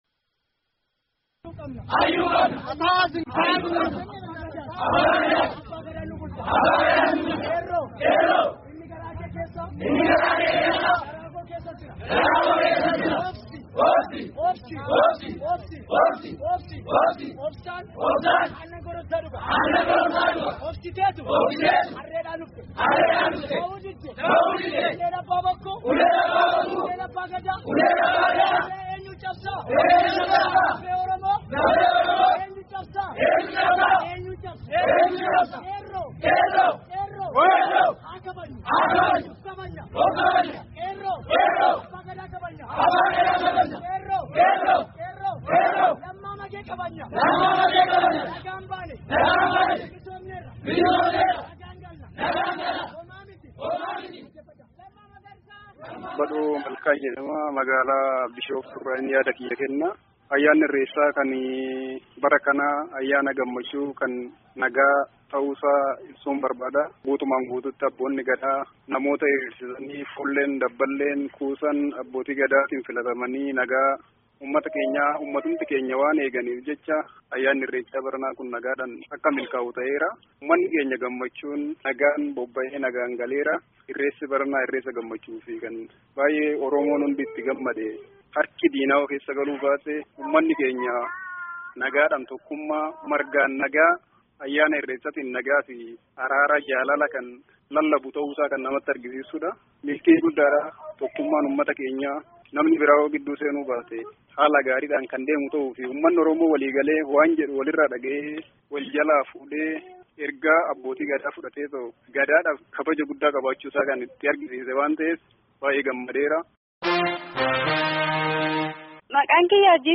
Bishooftuu: Irreeca bara 2017 Bakka Irreechi bara kanaa itti geggeeffame Bishooftuu fi naannawaa isheetti bilbiluu dhaan namoota itti hirmaatanii deebi’aa jiran haasofsiisnee jirra.